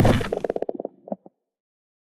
creaking_heart_fall.ogg